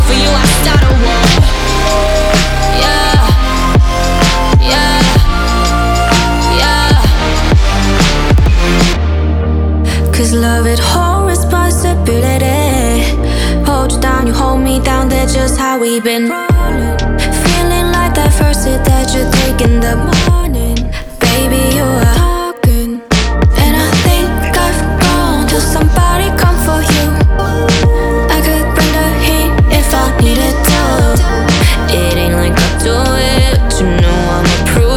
Скачать припев, мелодию нарезки
Pop K-Pop
2025-03-07 Жанр: Поп музыка Длительность